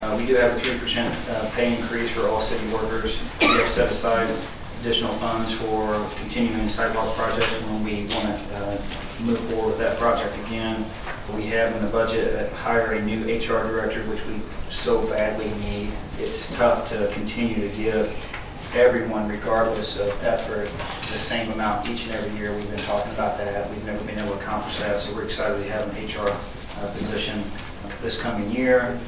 During the regular meeting of the council on September 20, Ward 2 Councilman Kirk Arends touched on some of the bullet points of the upcoming fiscal year’s budget, including a slight bump in salary for all city workers.